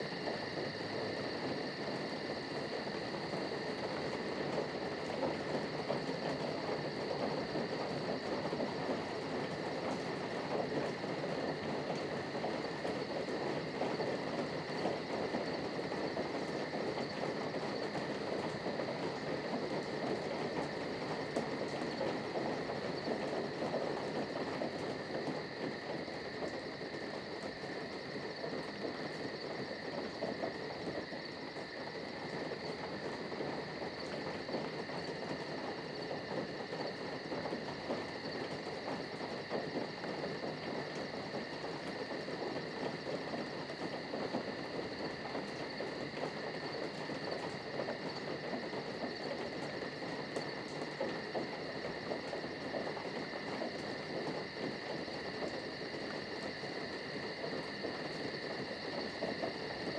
Inside Night Rain.ogg